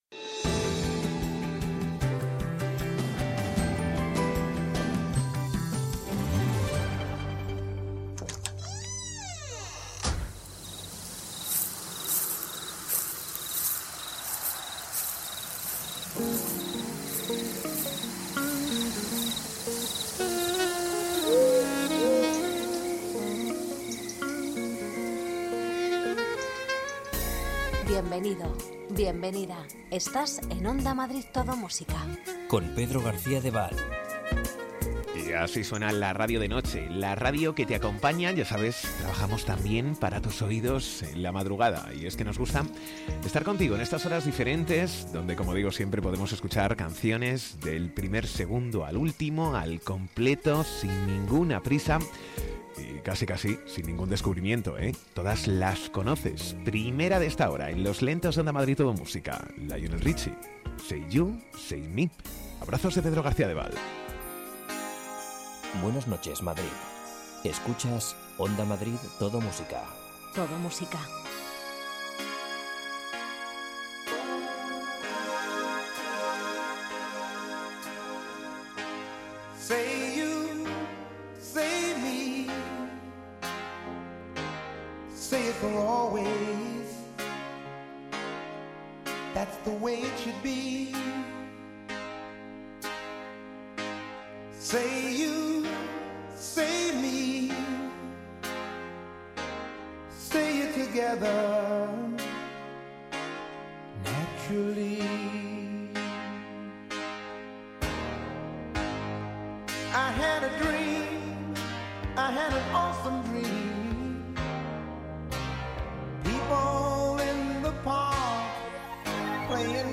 Ritmo tranquilo, sosegado, sin prisas.